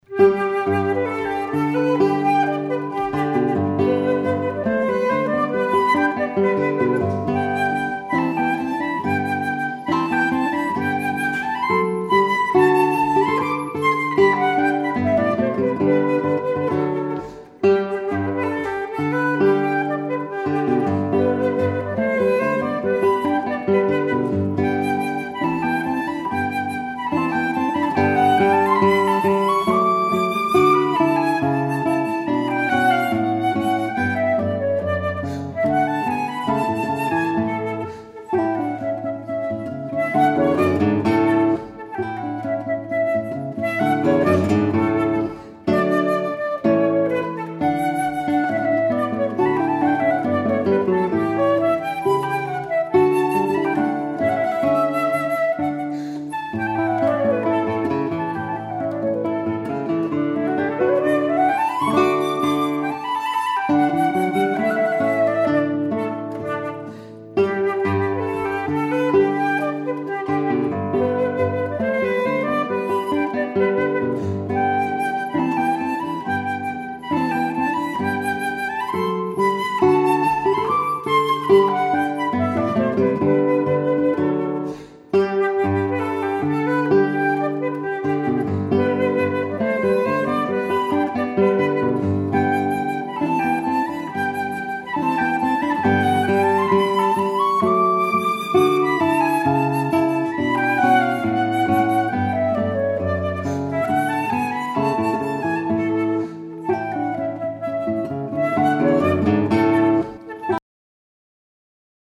for Flute and Guitar